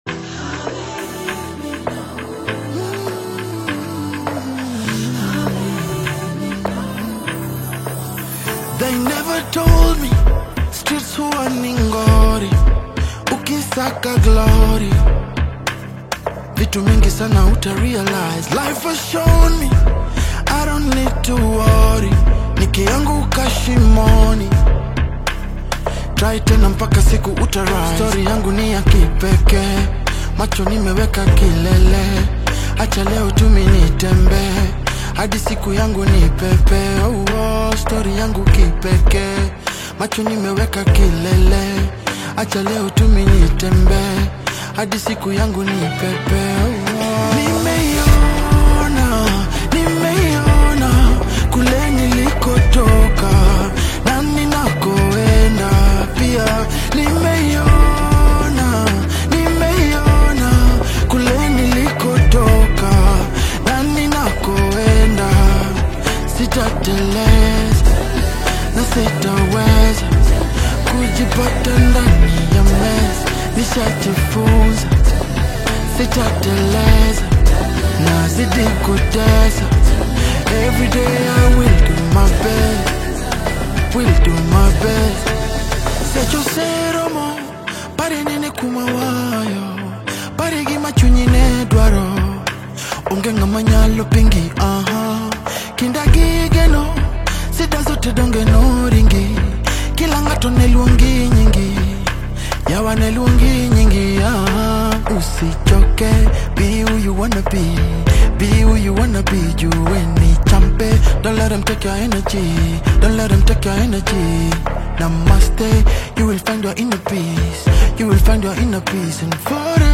Afro-Pop/Afro-Fusion single
smooth vocal delivery over modern production
With its melodic hooks and thoughtful storytelling